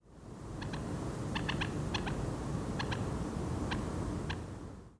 Possibly a variant of the kek, the individual notes are shorted and don’t have the typical “staple” shape of kek notes.
Moorhen kik sequence